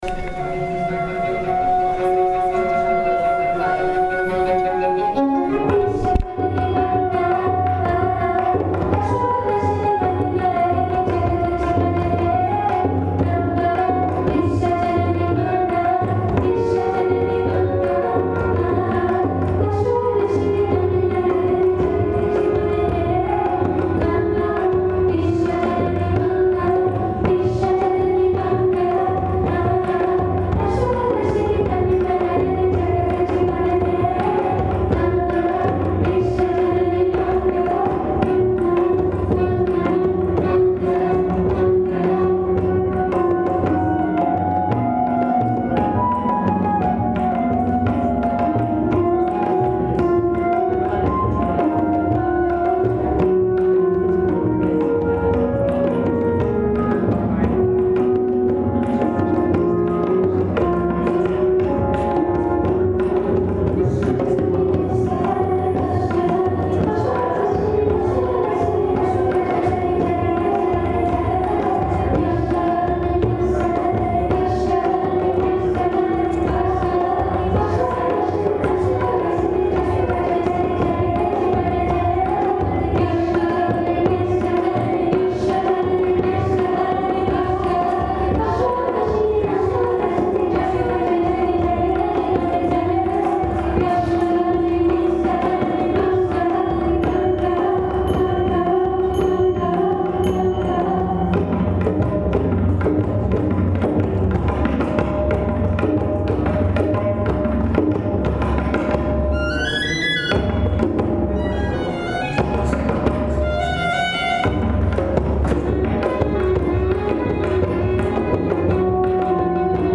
The musicians of Sangit Surabhi are an all-female vocal and instrumental ensemble based in Ottawa.
Sangit Surabhi Rehearsal: